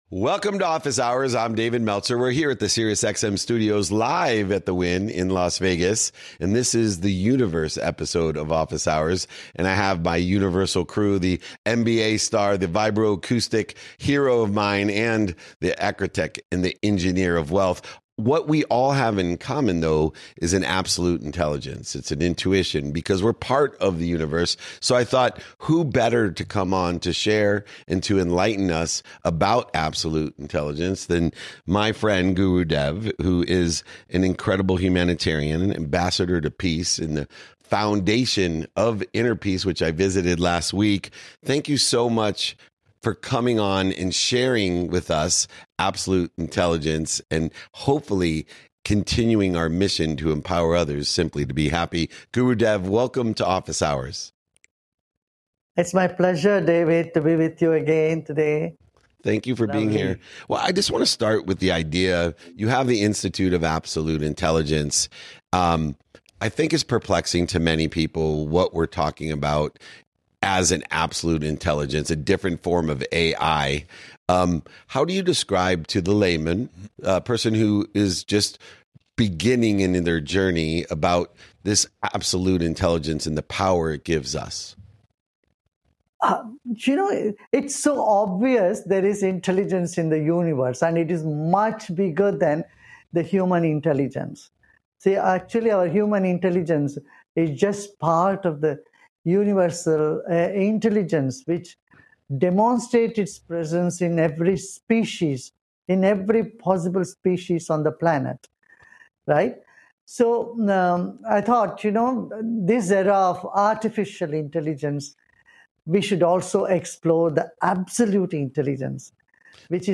In today’s episode, I sit down with Gurudev to talk about absolute intelligence and how it shapes the way we think, feel, and perform. We explore the idea that human intelligence is part of a larger universal consciousness, and how intuition plays a role in accessing it. Through conversations on meditation, breathwork, and mental hygiene, we break down practical ways to build resilience, increase focus, and stay present.